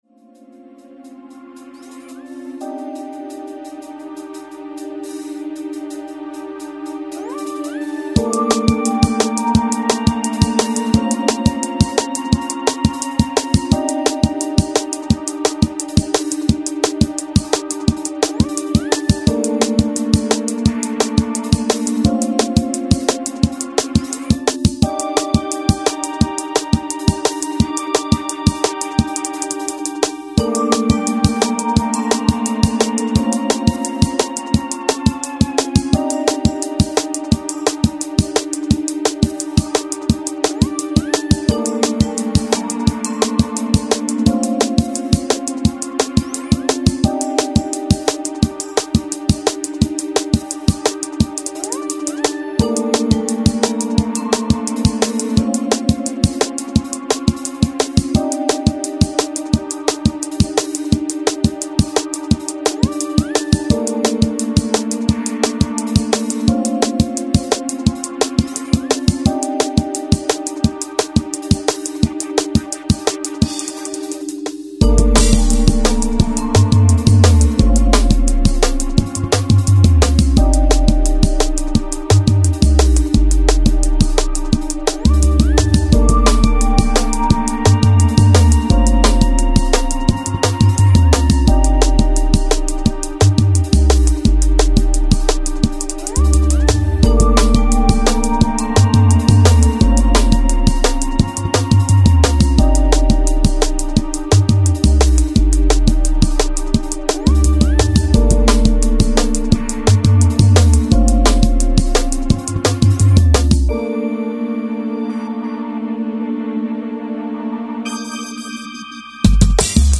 futuristic dark sounds
Techstep